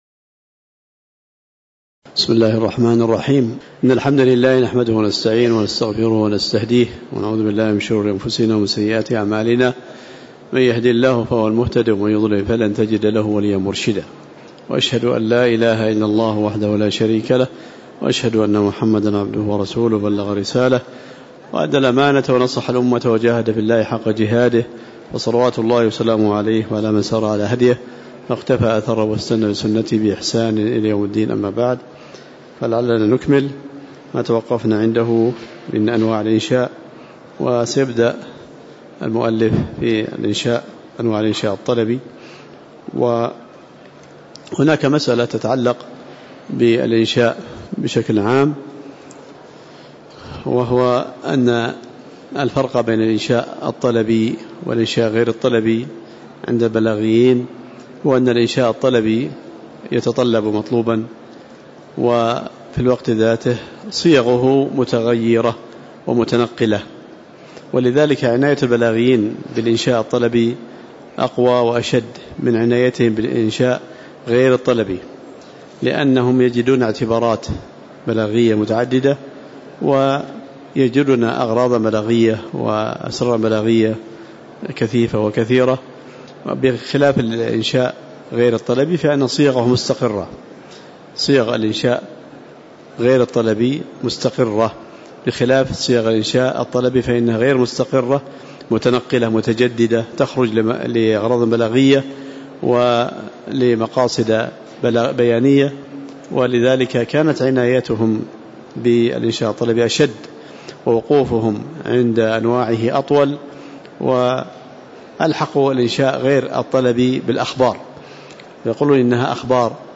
تاريخ النشر ١٠ صفر ١٤٤٠ هـ المكان: المسجد النبوي الشيخ